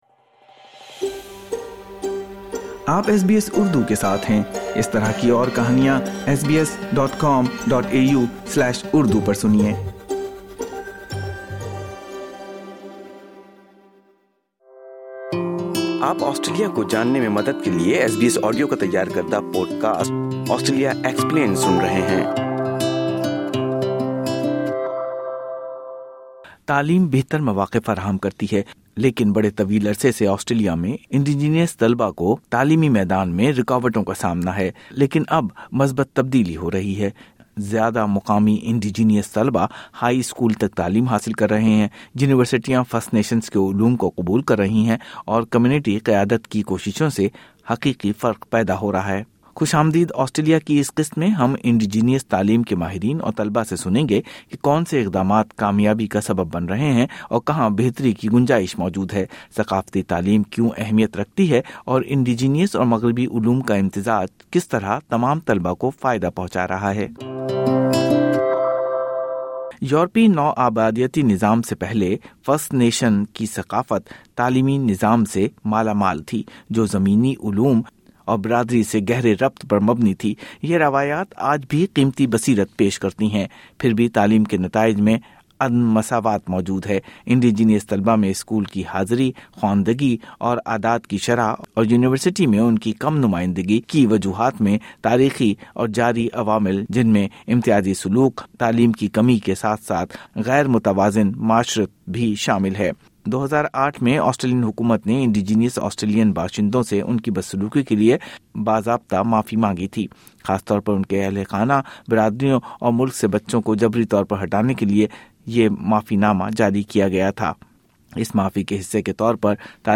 زیادہ اینڈیجینئس طلباء ہائی اسکول تک تعلیم حاصل کررہےہیں، یونیورسٹیاں فرسٹ نیشنز کے علم کو قبول کررہی ہیں، اور کمیونٹی قیادت کی کوششوں سے حقیقی فرق پیدا ہو رہا ہے۔ اس قسط میں، ہم اینڈیجینئس تعلیم کے ماہرین اور طلباء سے سنیں گے کہ کونسے اقدامات کامیابی کا سبب بن رہے ہیں، ثقافتی تعلیم کیوں اہمیت رکھتی ہے، اور اینڈیجینئس اور مغربی علوم کا امتزاج کس طرح تمام طلباء کو فائدہ پہنچا رہا ہے۔